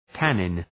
Προφορά
{‘tænın}